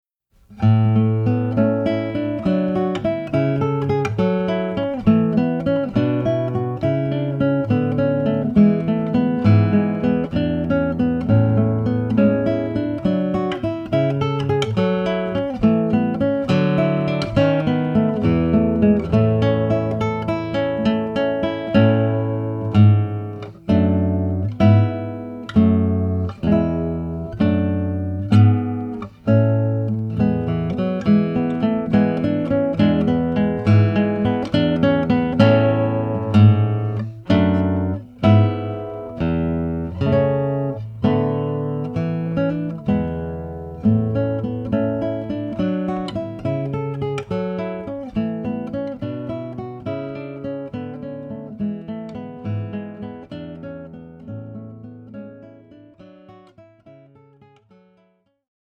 Audio Examples (solo guitar)
Classical, Baroque, Renaissance, Traditional Wedding Music